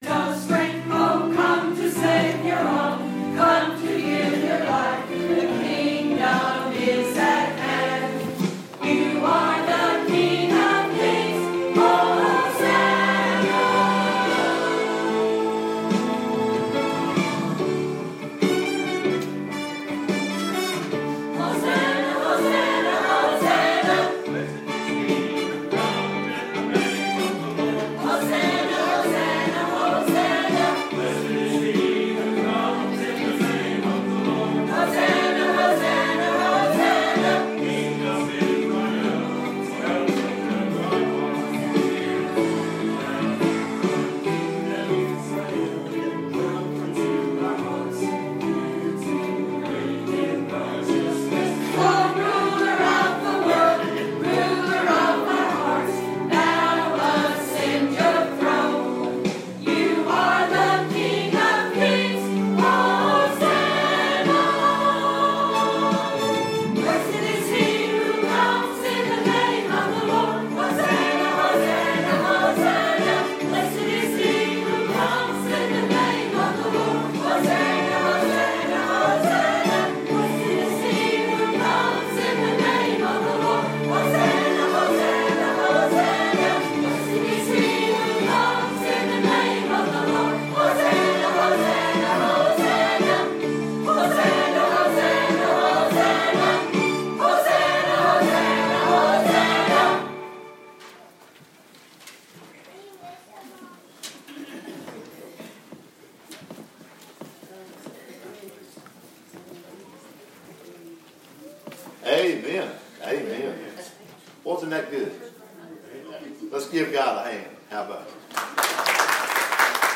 The Greatest Sermon
matthew-28-1t8-the-greatest-sermon_1.mp3